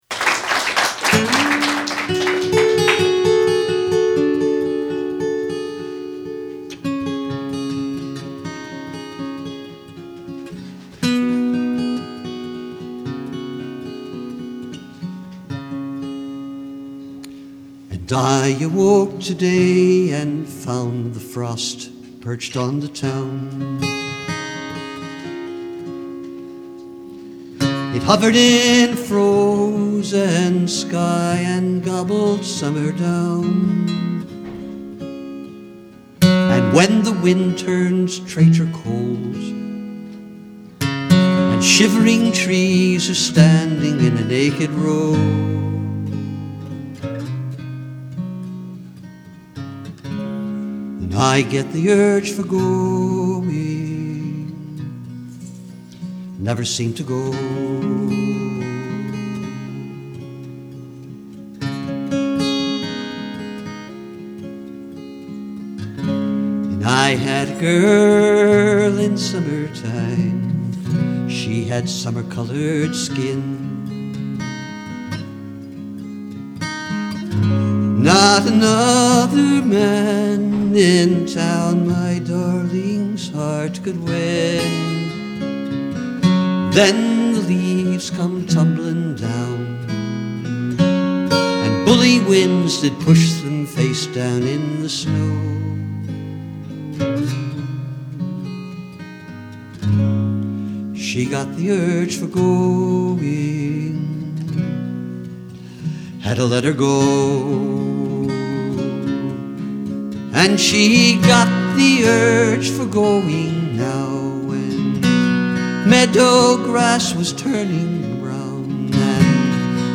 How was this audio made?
A live-in-concert recording